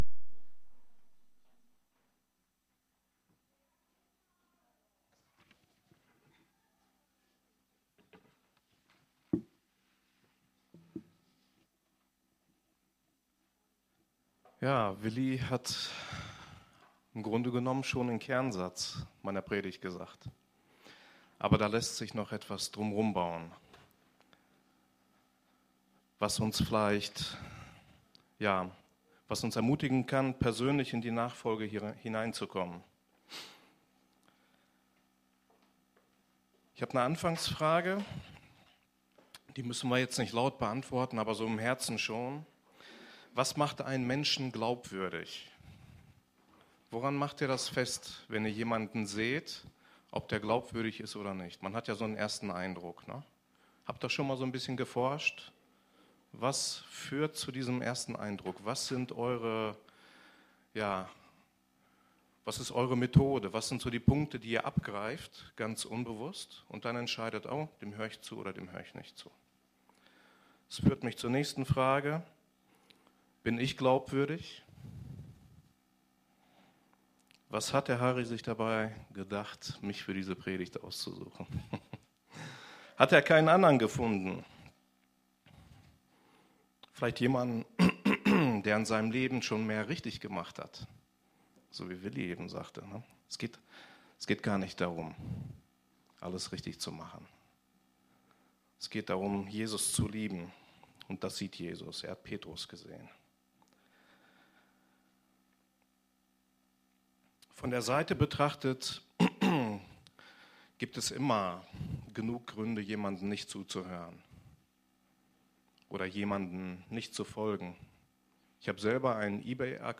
Predigt vom 14. April 2019 – efg Lage